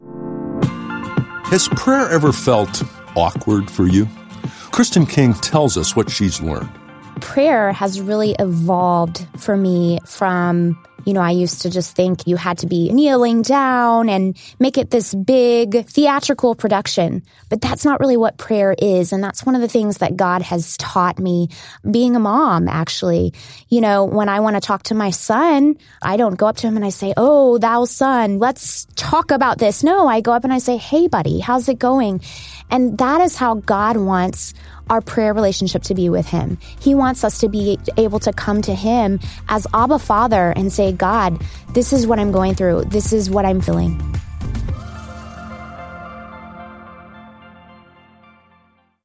Each day, you’ll hear a short audio message with simple ideas to help you grow in your faith. The messages include real stories, personal thoughts, and tips you can use right away.